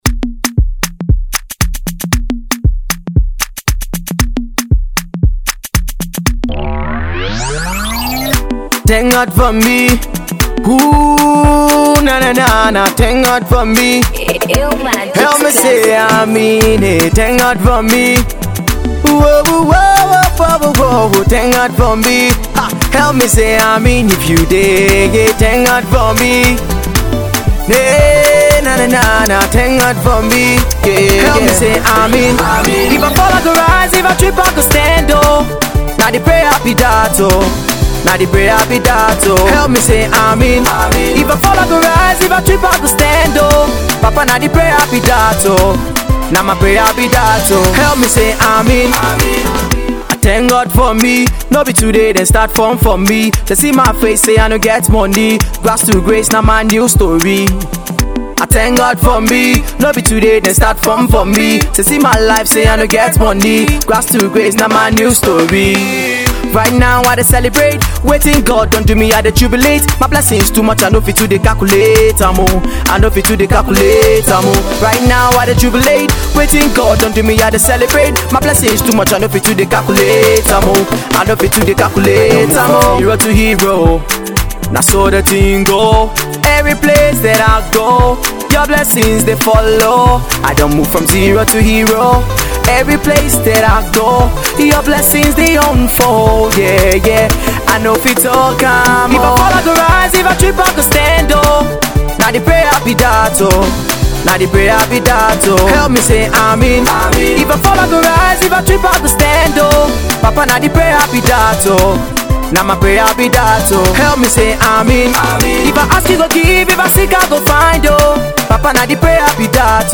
Jos based sensational Gospel singer and song writer